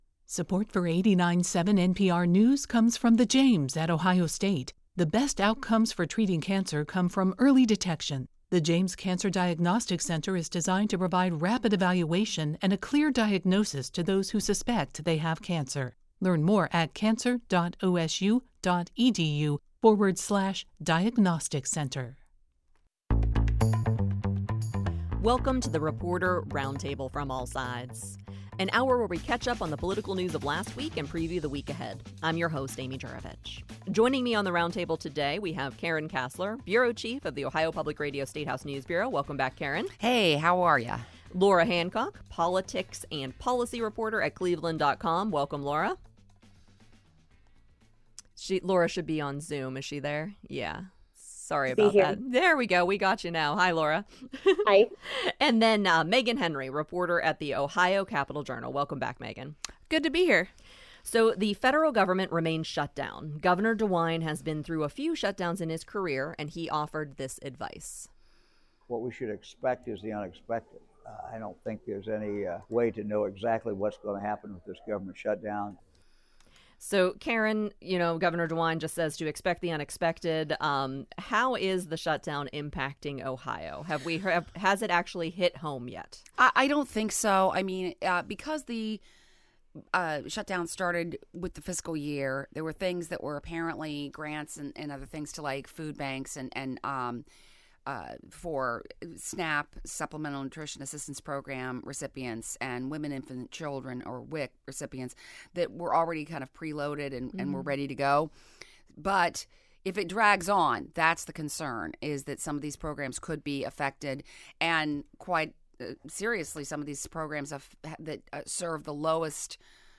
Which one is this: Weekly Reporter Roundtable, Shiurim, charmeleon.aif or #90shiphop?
Weekly Reporter Roundtable